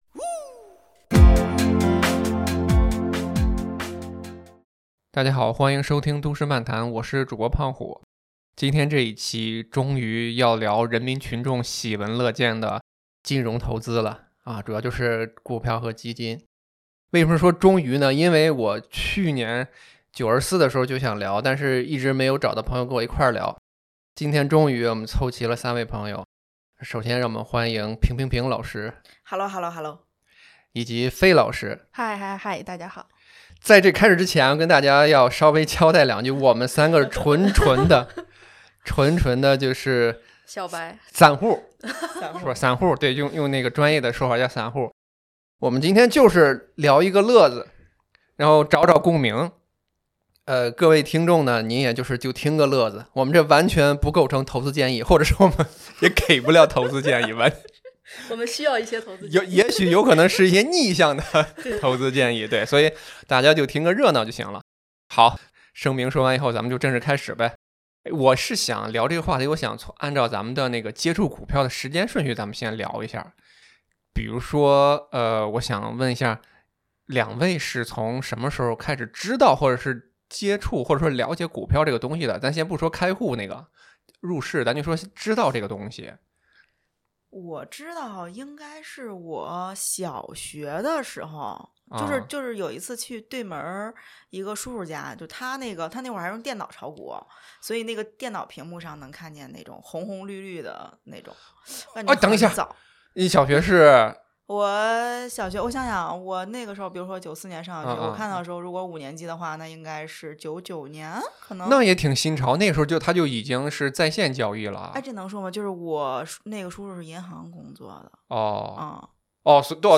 本期节目所有内容纯属三个“股市乐子人”的真实搞笑投资血泪史，不构成任何投资建议，您就权且听个乐呵。